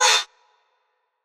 Vox 1 [ sexy ].wav